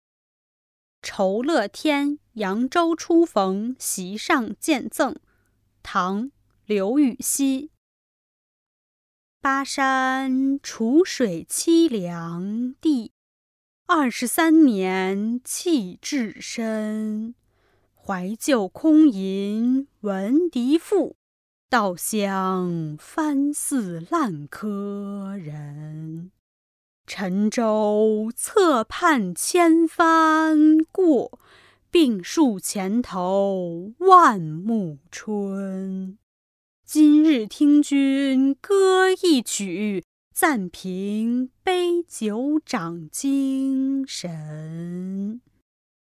［唐］刘禹锡 《酬乐天扬州初逢席上见赠》（读诵）